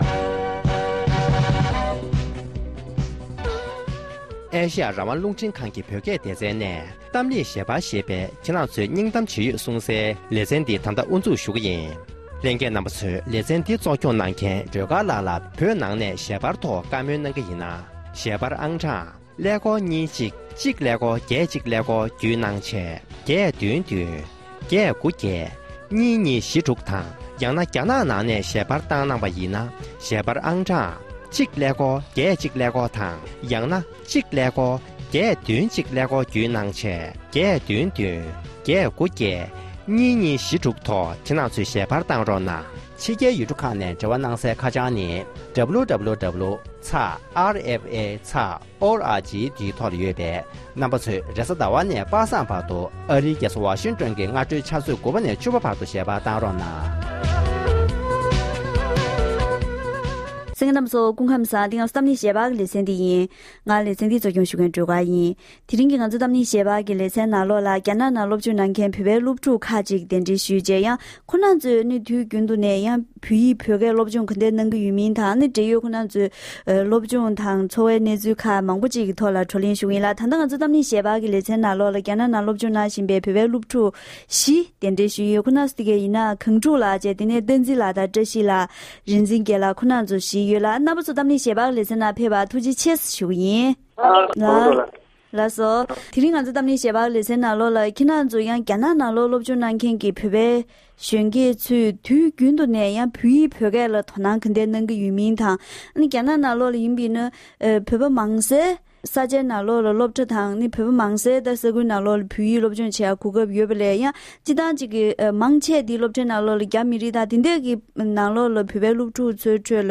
རྒྱ་ནག་ནང་ཡོད་པའི་བོད་པའི་སློབ་མ་བཞི་དང་ལྷན་དུ་གླེང་བ།